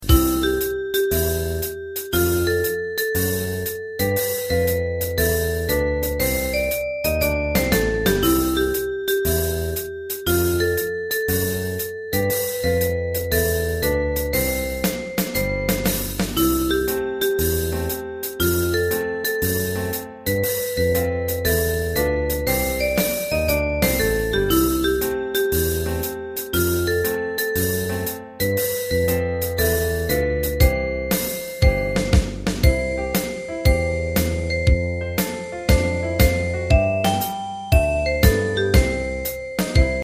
大正琴の「楽譜、練習用の音」データのセットをダウンロードで『すぐに』お届け！
カテゴリー: ユニゾン（一斉奏） .
映画音楽・軽音楽